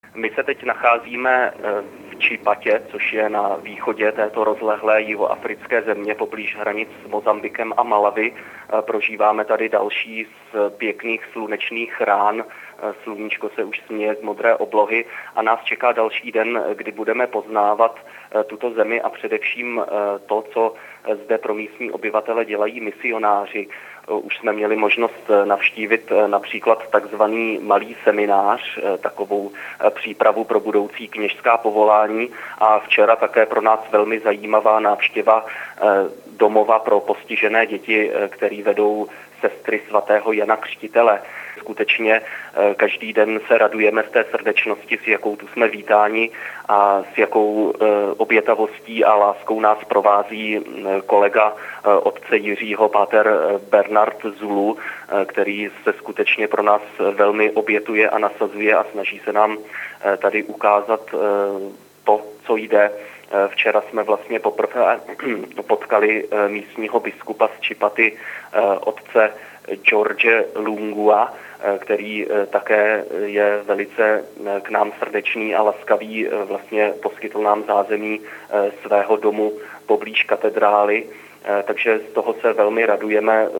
Na začátku týdne jsme se s nimi poprvé telefonicky spojili, do pondělí Svatého týdne jsou hosty v diecézi Chipata na východě země, poblíž hranic s Mosambikem a Malawi.
O průběhu cesty v pondělním telefonátu hovořil nejprve kolega